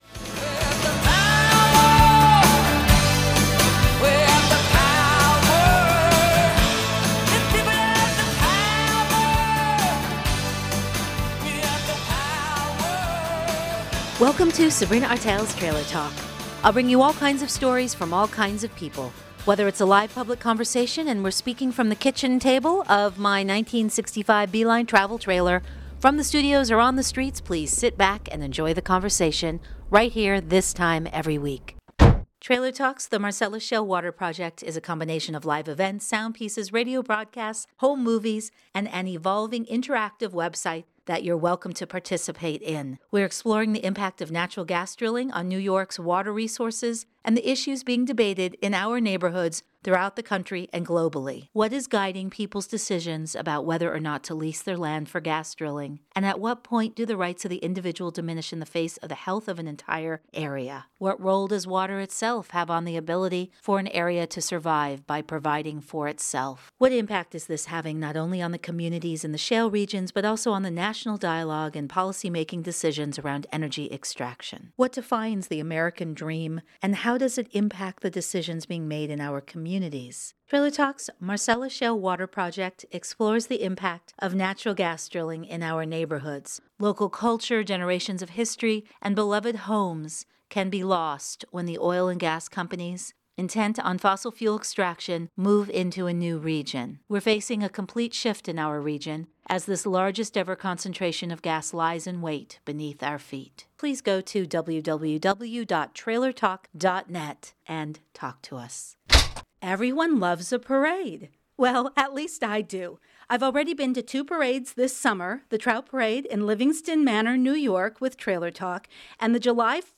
This road session takes us to the zany community event celebration all things fishy in the Sullivan County Catskills, the 7th Annual Trout Parade in Livingston Manor, NY .